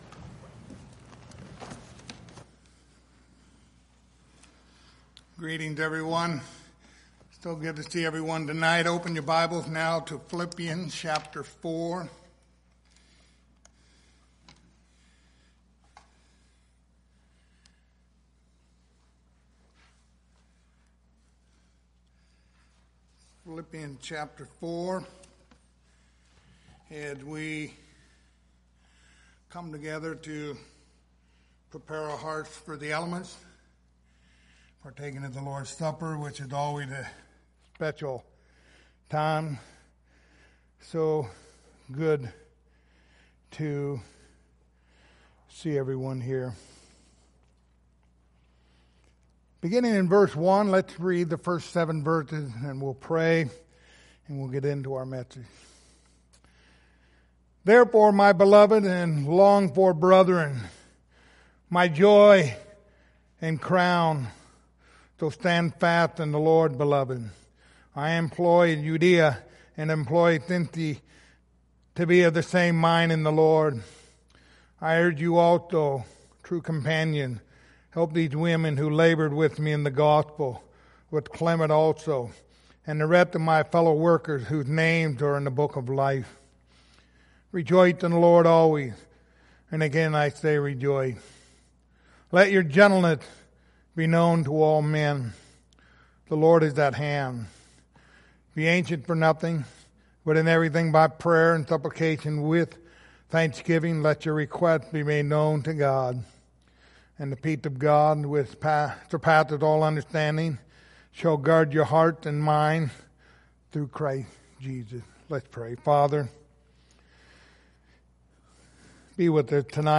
Passage: Philippians 4 Service Type: Lord's Supper